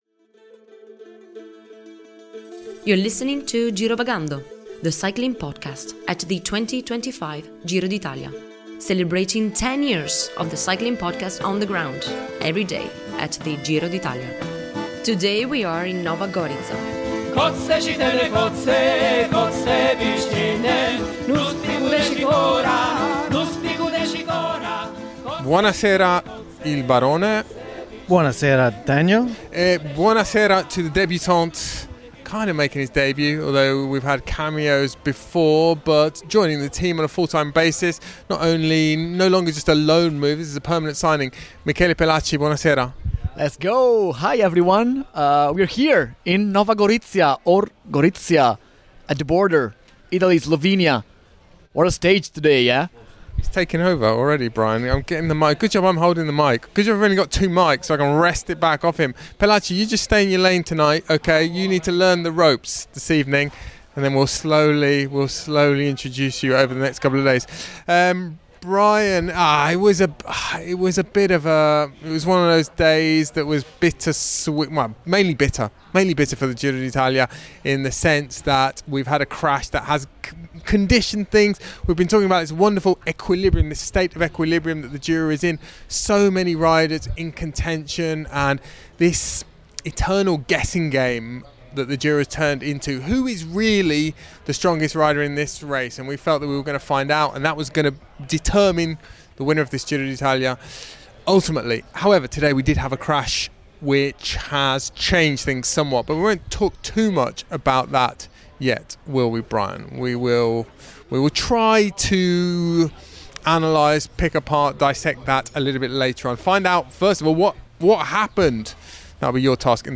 Join us for daily coverage of the Giro d’Italia recorded on the road as the race makes its way from Albania to Rome.